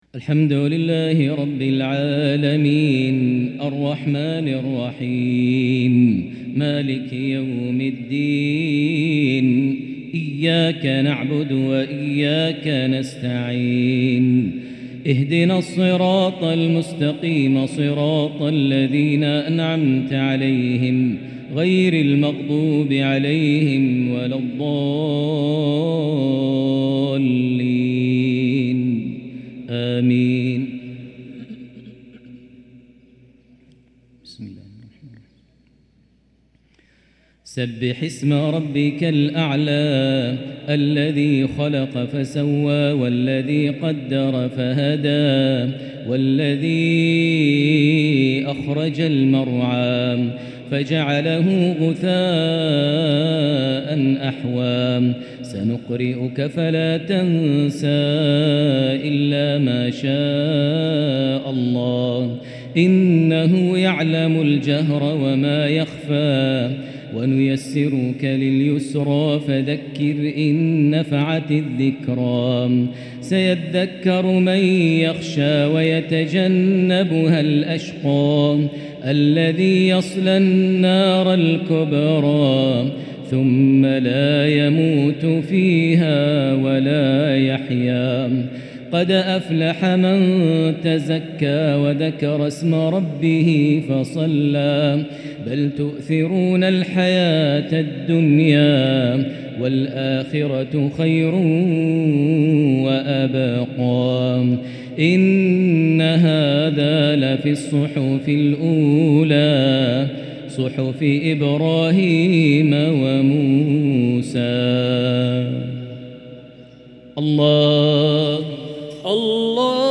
تلاوة لـ سورتي الأعلى والغاشية | صلاة الجمعة 5-7-1444هـ. > 1444 هـ > الفروض - تلاوات ماهر المعيقلي